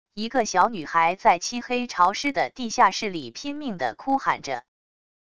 一个小女孩在漆黑潮湿的地下室里拼命地哭喊着wav音频